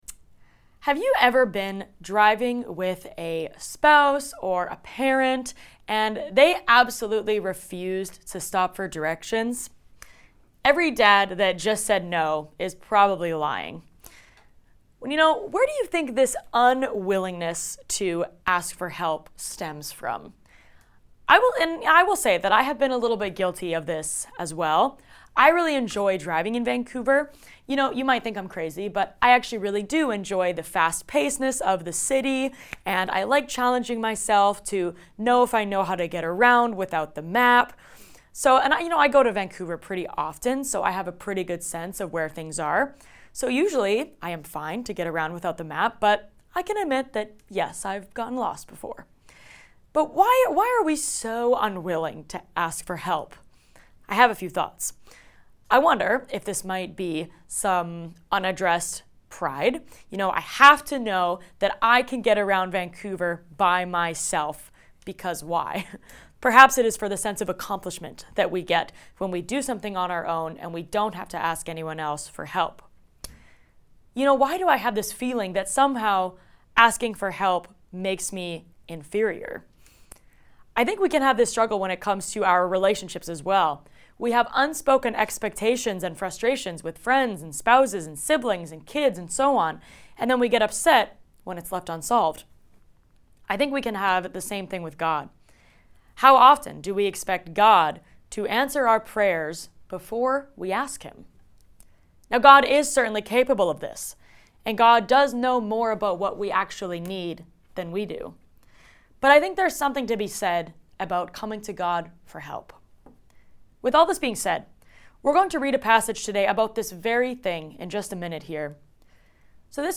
Words of Jesus Current Sermon Knock Knock Have you ever felt like bringing your requests to God is too daunting, or like asking Him for something feels too bold? This week, we'll explore what it means to confidently approach God with our needs and seek His guidance.